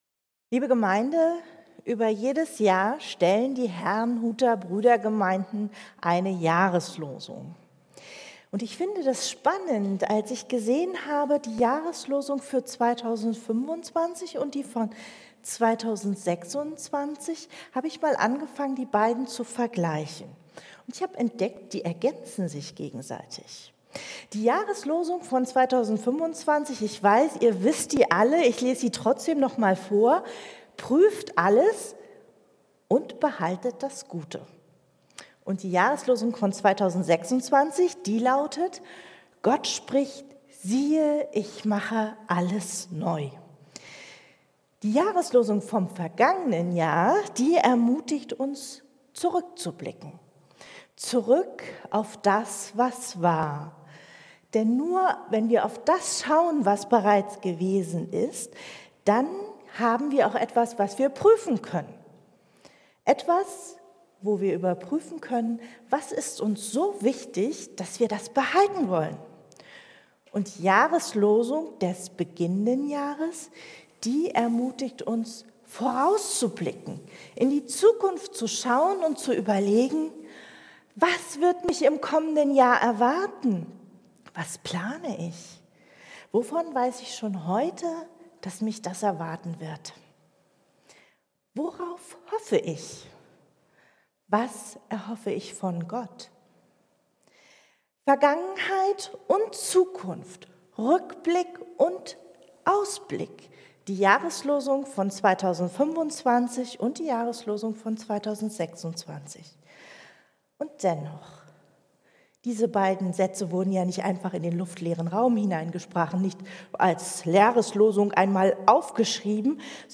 Zwei Jahreslosungen, ein Spannungsfeld: Rückblick und Aufbruch ~ Christuskirche Uetersen Predigt-Podcast Podcast